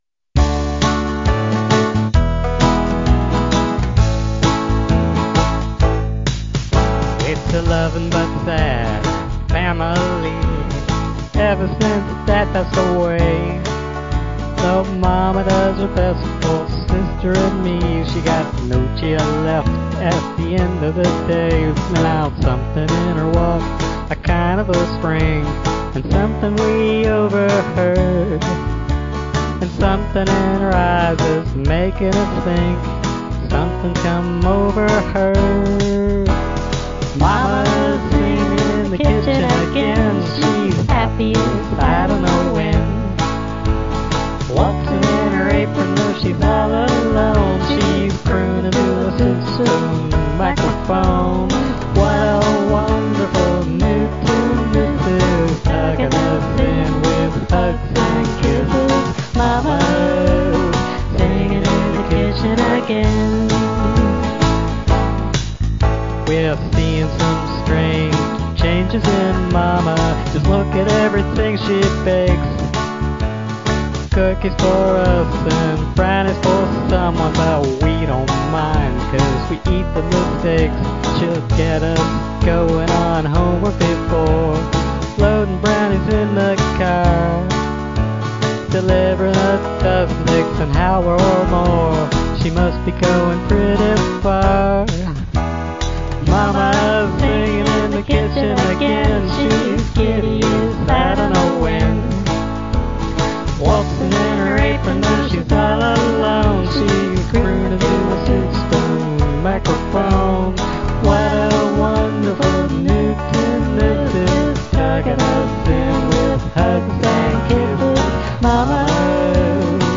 medium-tempo country or pop, 2 child (girl pref.) voices